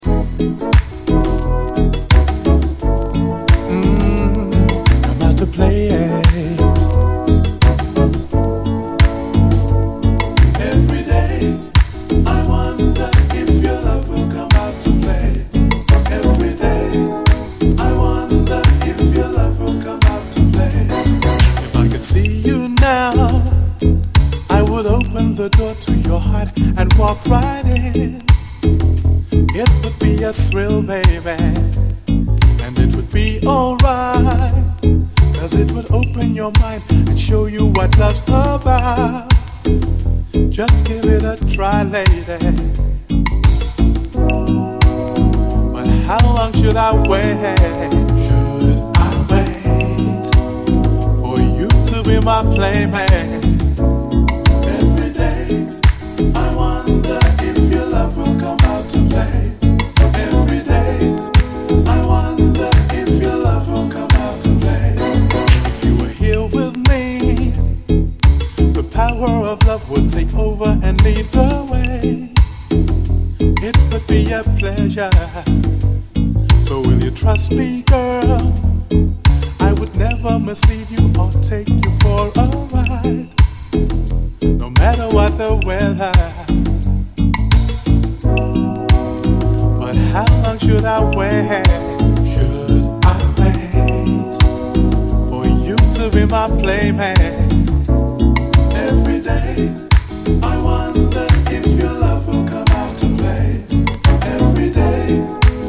REGGAE
ライト＆シルキーなシンセ・サウンドが非常に気持ちいい、メロウUKラヴァーズ！
ライト＆シルキーなシンセ・サウンドが心地よい！